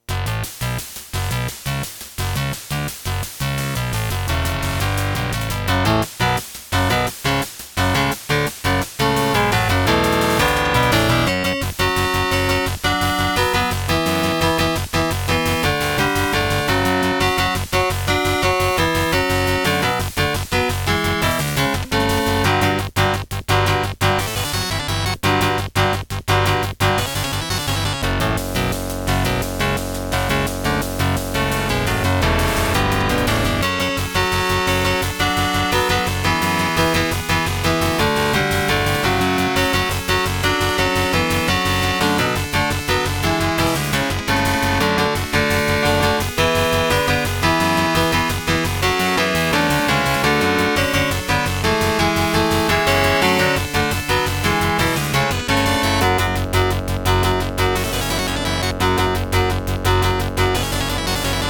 Creative SoundBlaster 16 ct2740
* Some records contain clicks.